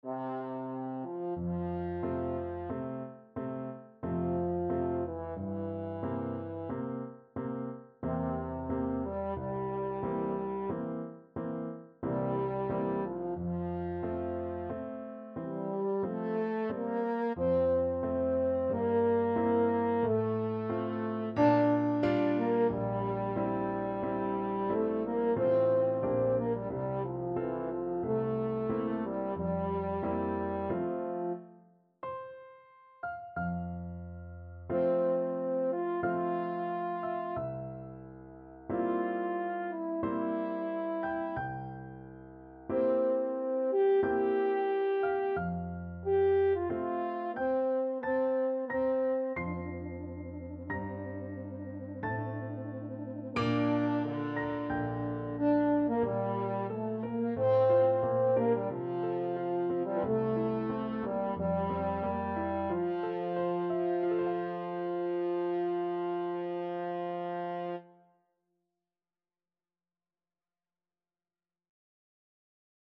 French Horn
3/4 (View more 3/4 Music)
Adagio =45
Classical (View more Classical French Horn Music)